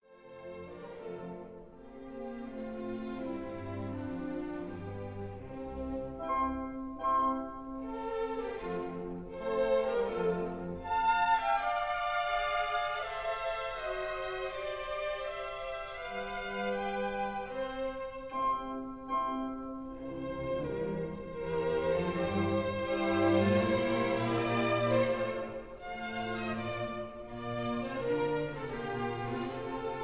in C major